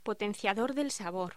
Locución: Potenciador de sabor
voz